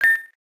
GUI sel decision.ogg